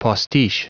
Prononciation du mot postiche en anglais (fichier audio)
Prononciation du mot : postiche